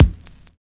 FINE BD    6.wav